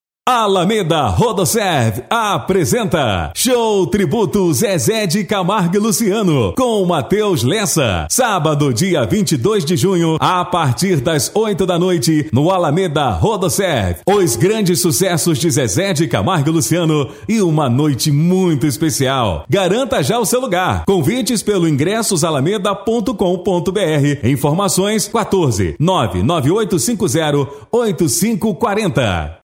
OFF SPOT RÁDIO ALAMEDA:
Impacto
Animada